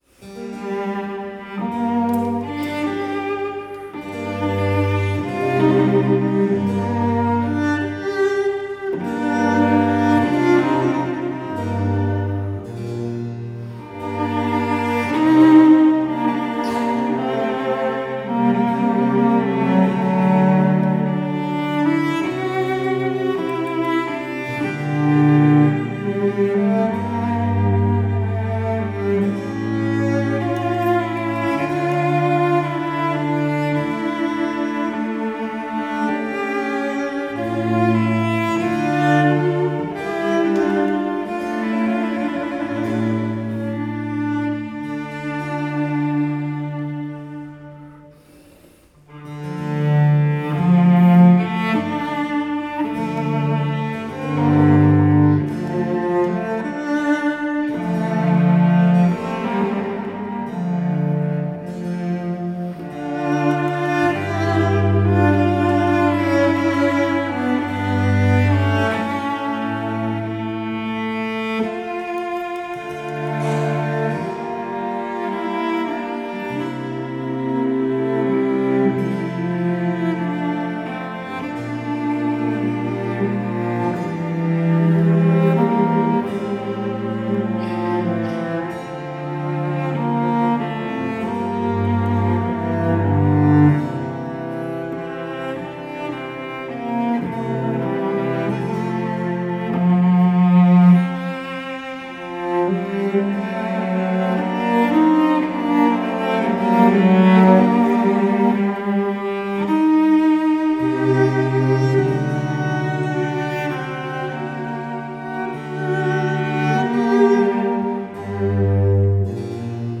largo
02-Concerto-for-Two-Cellos-in-G-Minor-RV-531_-II.-Largo.mp3